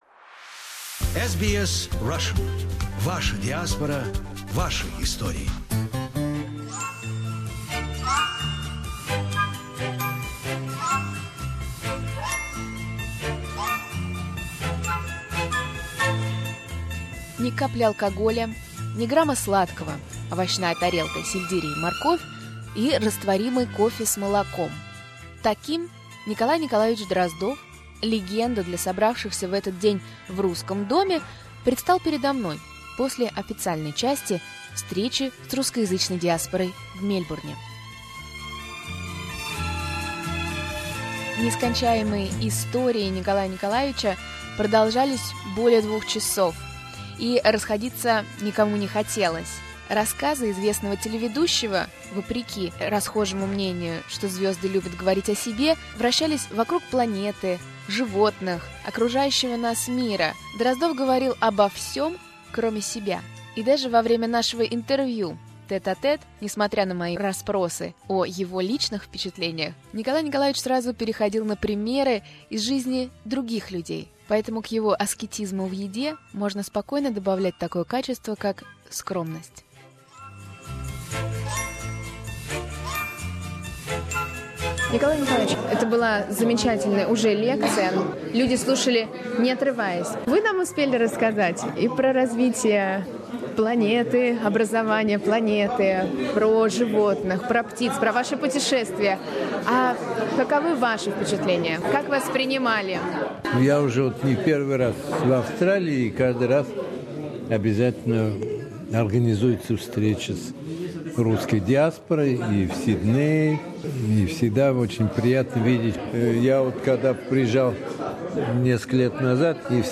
Russian speaking community in Melbourne gathered for a meeting with a man who was and still remains a legend for them. Nikolay Drozdov, a scientist, journalist and a presenter of the popular TV show “In the world of animals” is visiting Australia and was invited to give a speech.